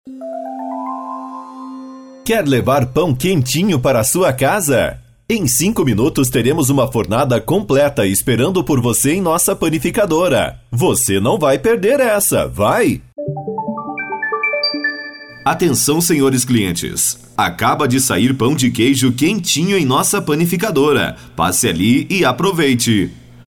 Com nosso sistema você terá mensagens pré-gravadas com a mesma qualidade e entonação de um locutor profissional, nada robotizado.
locutor-virtual-01.mp3